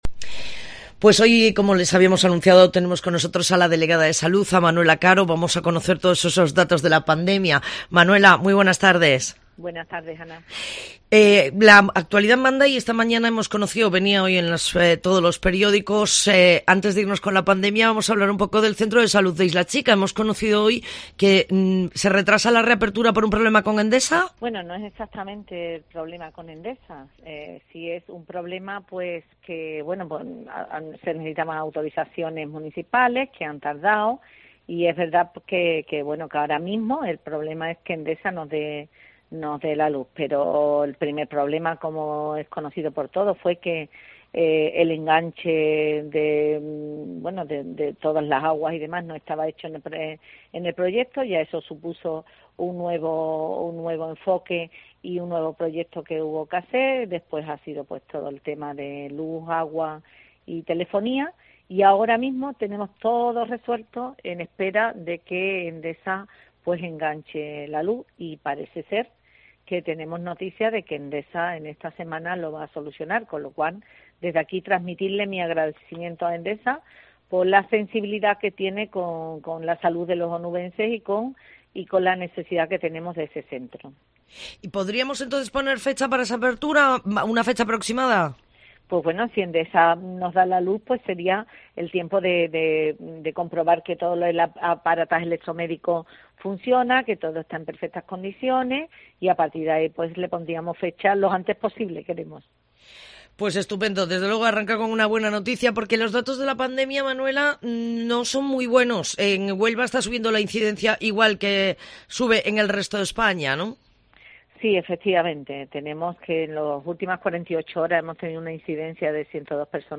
Se trata del último fleco que quedaba por lo que según ha avanzado la delegada de Salud Manuela Caro en los micrófonos de la COPE la apertura de este centro de salud será muy pronto: